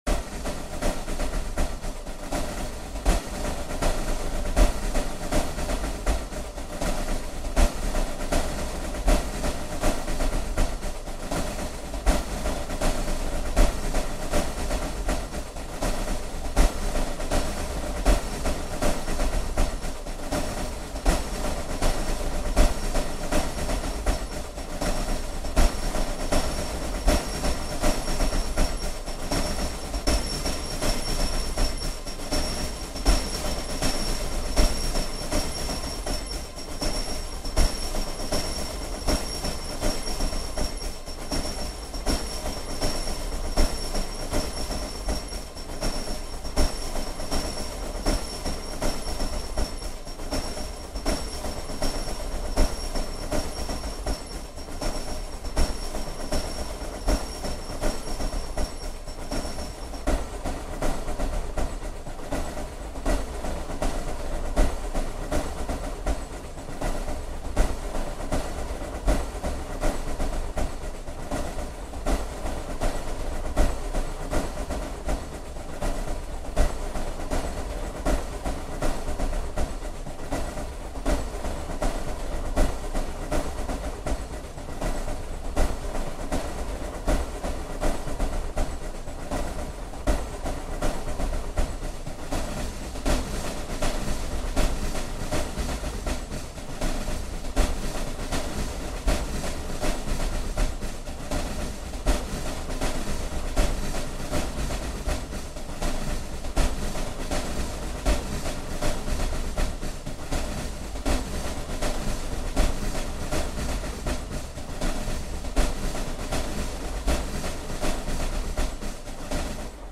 Chiang Mai children's parade